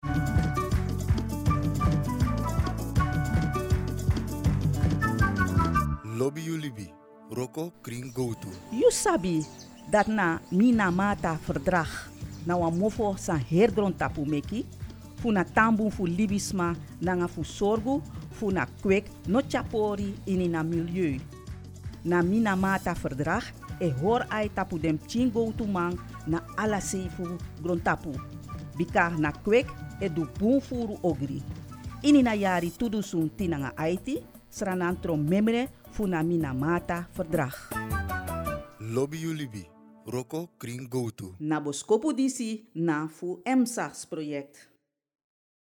EMSAGS Sranan Radiospot 3